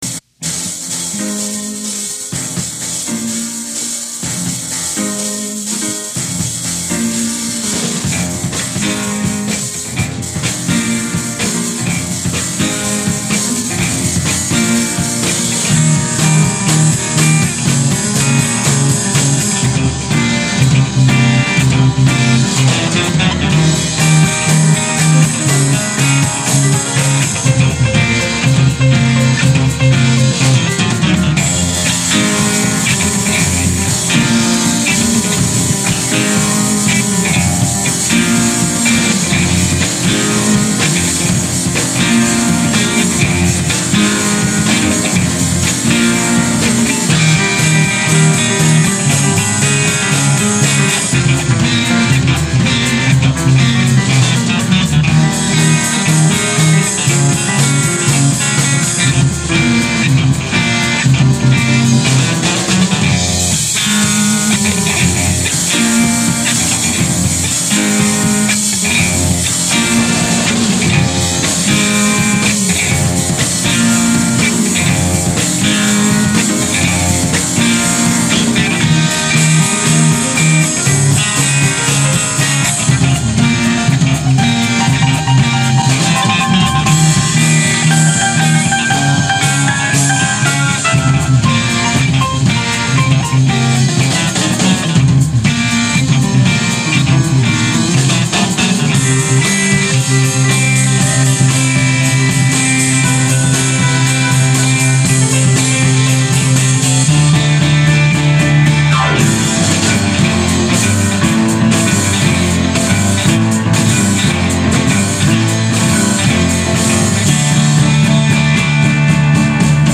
drums and keyboards
guitar